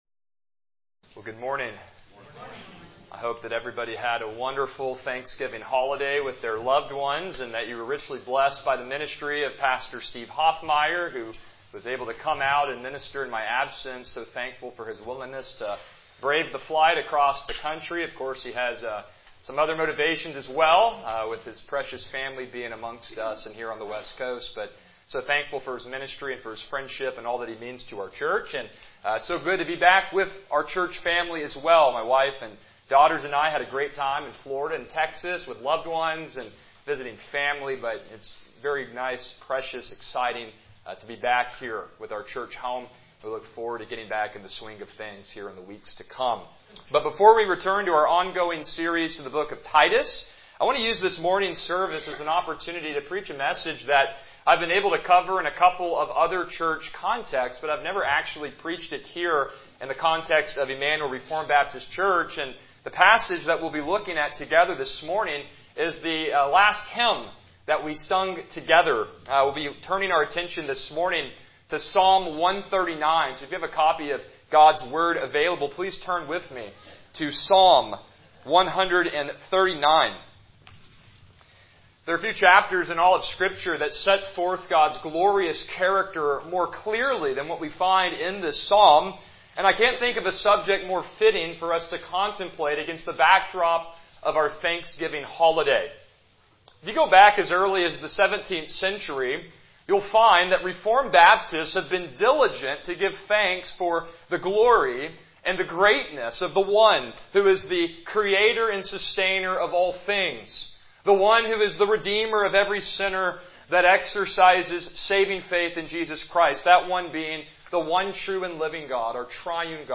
Passage: Psalm 139 Service Type: Morning Worship « Chapter 14.2-3